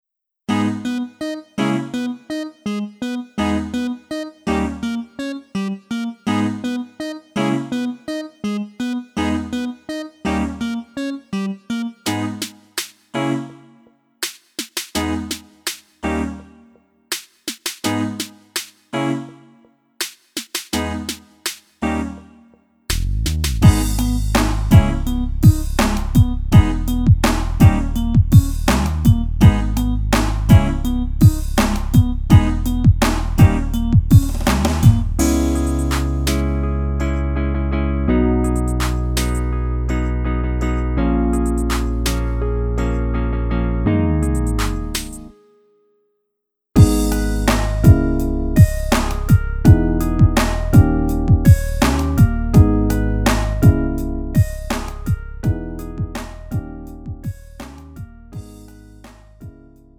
축가 및 결혼식에 최적화된 고품질 MR을 제공합니다!
음정 -1키
장르 가요